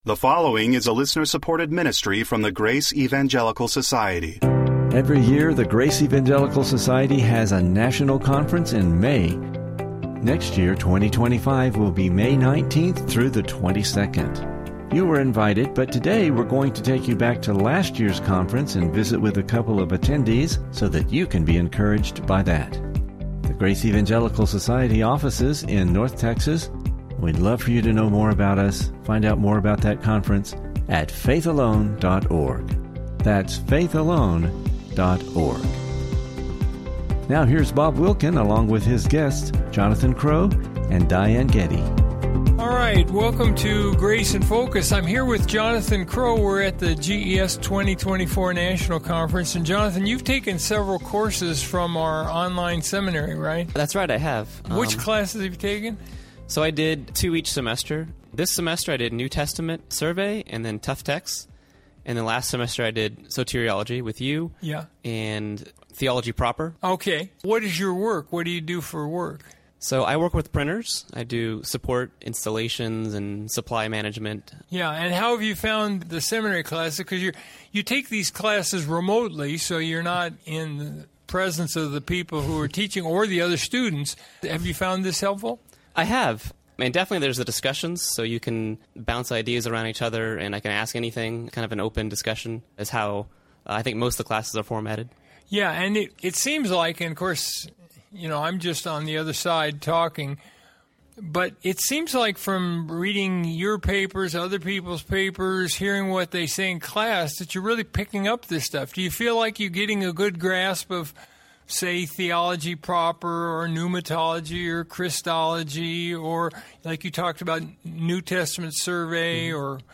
GES National Conference 2024–Attendee Interview
One is a GES Seminary student. Another was a first timer at our National Conference.